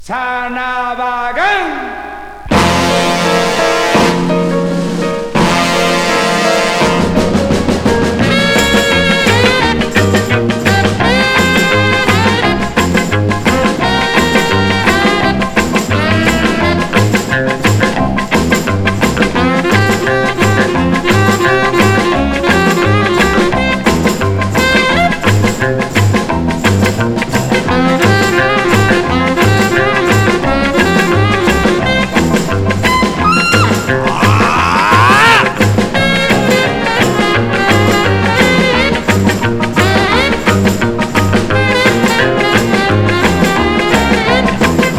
金管楽器の様々な表情、ストリングスにフルートが醸し出す魅惑さ、打楽器が生み出す土着さと洗練さを織り成す強烈かつ大興奮。
Jazz, Latin, Easy Listening, Mambo　USA　12inchレコード　33rpm　Mono